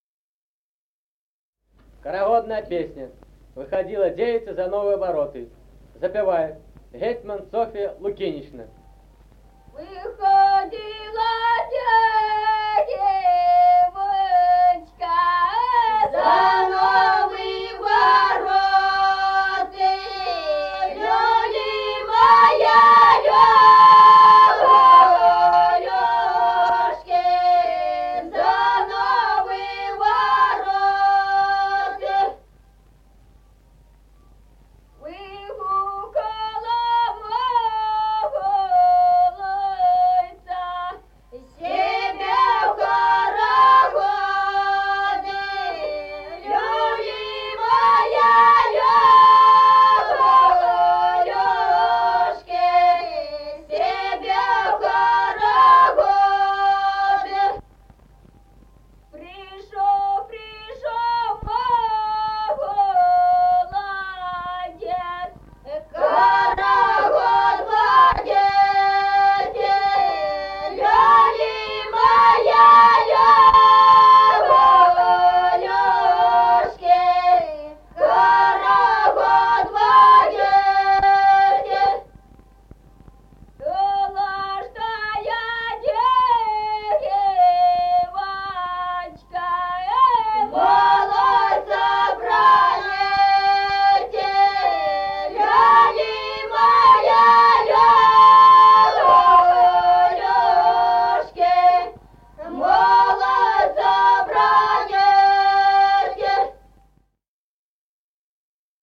Народные песни Стародубского района «Выходила девочка», карагодная.
1953 г., с. Мишковка.